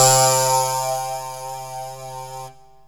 SYNTH GENERAL-4 0003.wav